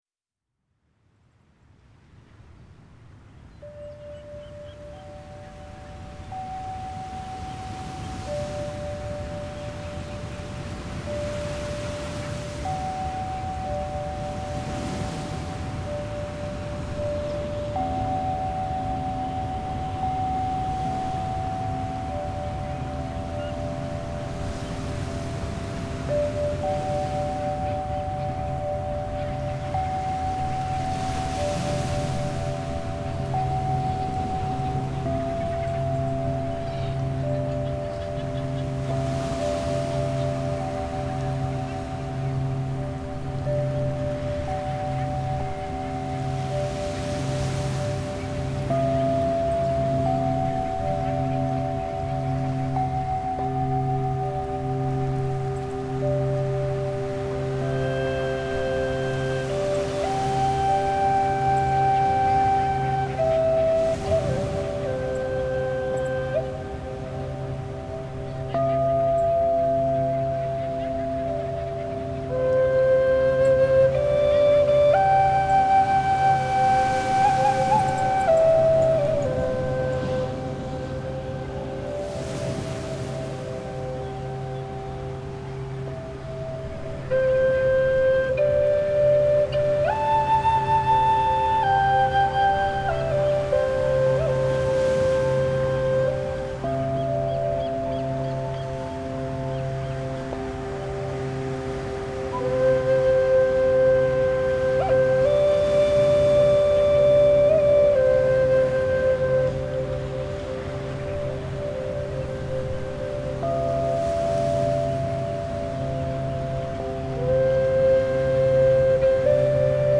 Under the surface, multiple layers of audible and inaudible tones, frequencies, and energies work synergistically with your brain, opening up new neural pathways, nudging existing ones, and facilitating profound coherence in your system.
It’s a pleasant audio experience, but it’s not like music or even white noise. (Don't worry, there are no new-agey harps or synthesizers that get boring.)
Don’t be fooled – this is not just beautiful music – it is the design and technology infused in the soundtrack that evokes the awareness and relaxed focus.